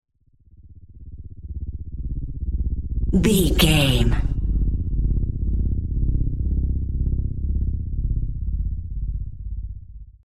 Dark Drone Chopper
Sound Effects
Atonal
magical
mystical